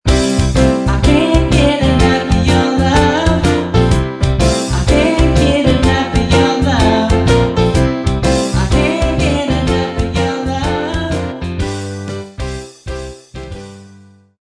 Samples Of Cover Tunes With Vocals